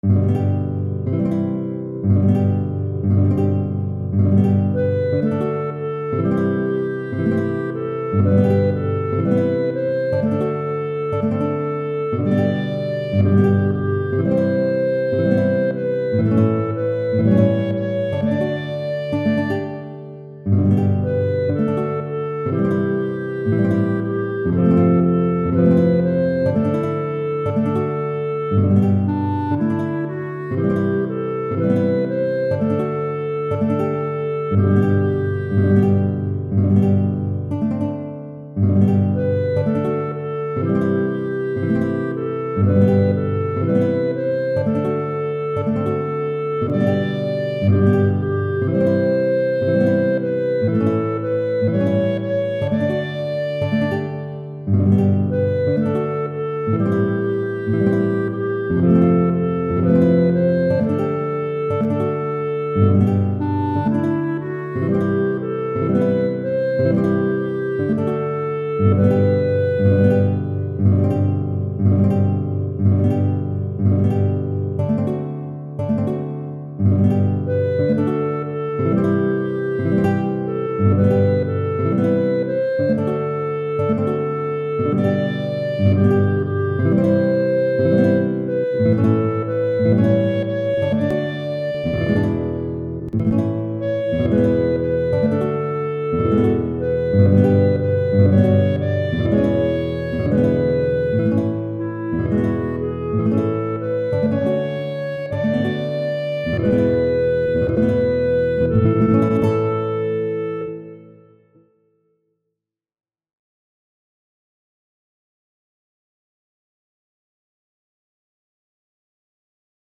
Computer audio
(clarinet for voice)
for solo voice and guitar